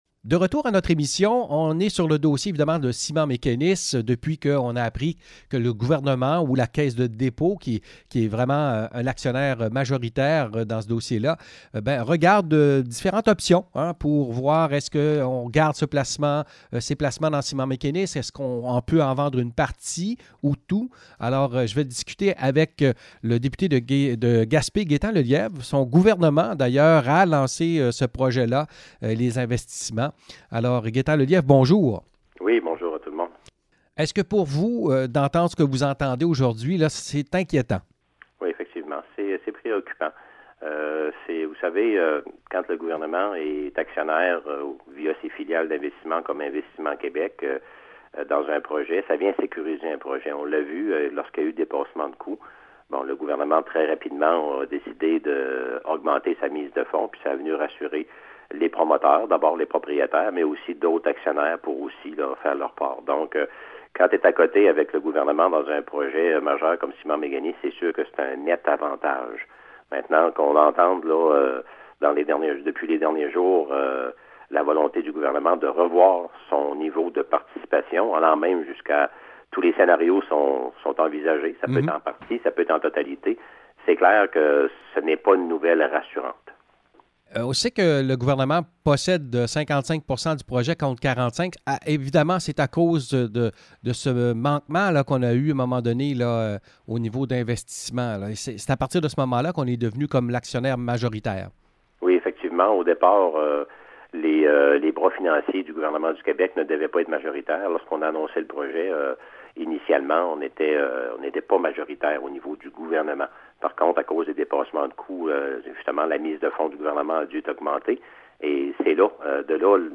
Écoutez nos entrevues sur le sujet.